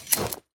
unequip_wolf2.ogg